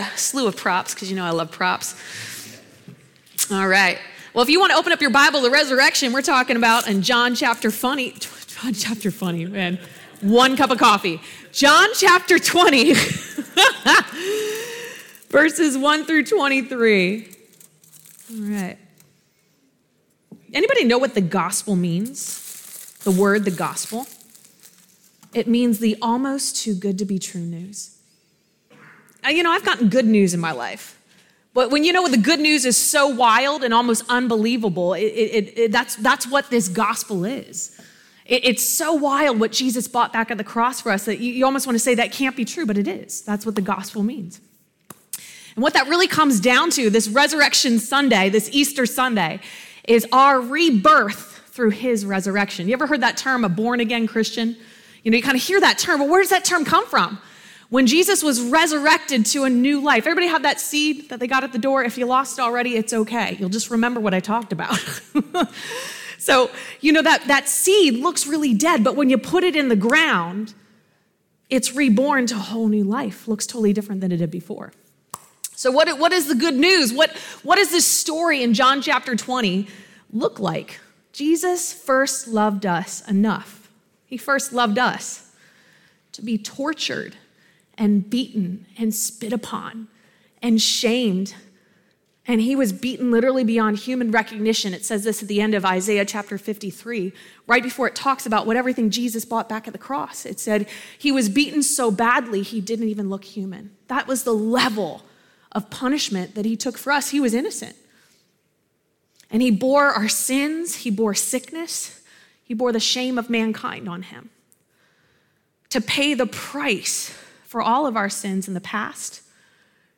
Sunday AM Service